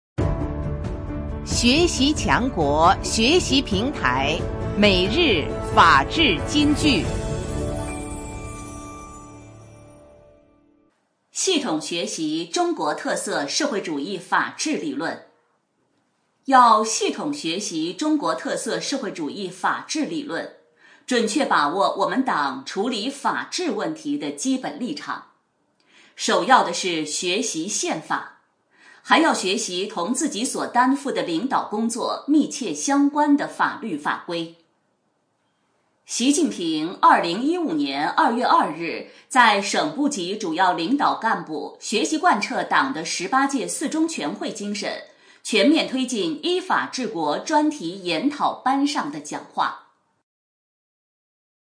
每日法治金句（朗读版）|系统学习中国特色社会主义法治理论 _ 学习宣传 _ 福建省民政厅